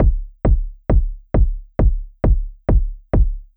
GAR Beat - Mix 7.wav